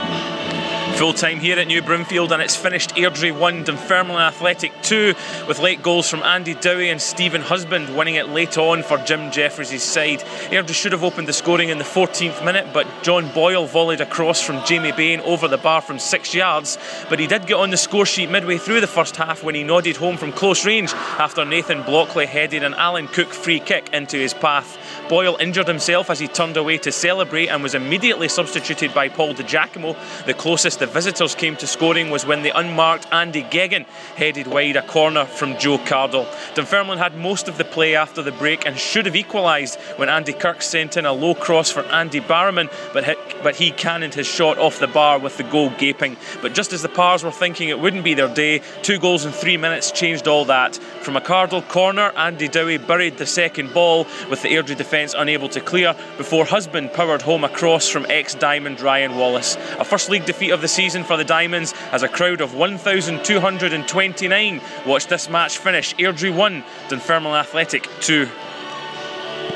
FT report: Airdrie 1 Pars 2.